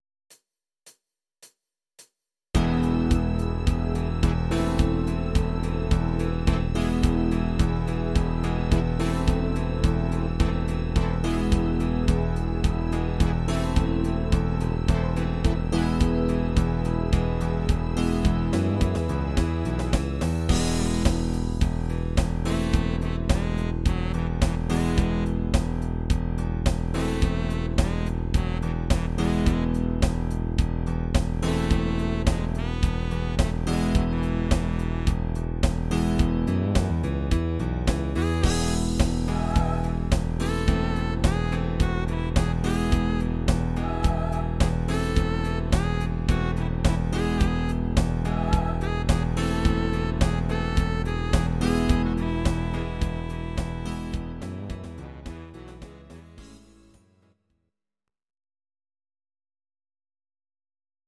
These are MP3 versions of our MIDI file catalogue.
Please note: no vocals and no karaoke included.